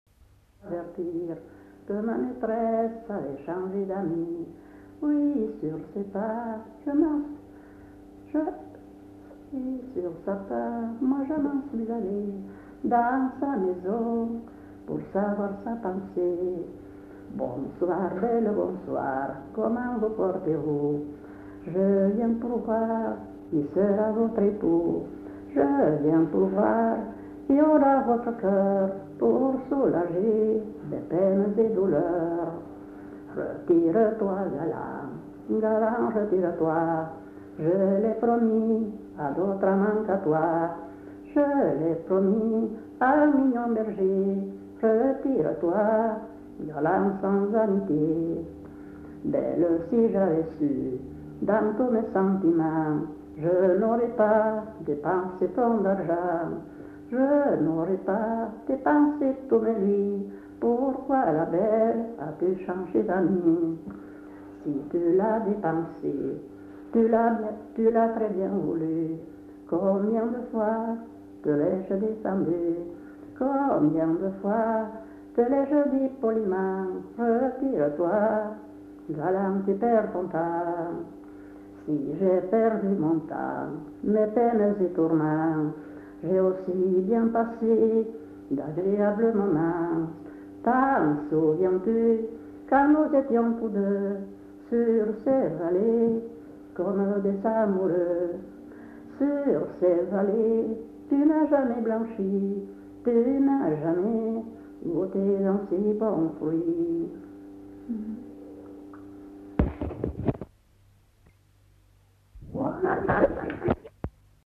Aire culturelle : Marsan
Lieu : [sans lieu] ; Landes
Genre : chant
Effectif : 1
Type de voix : voix de femme
Production du son : chanté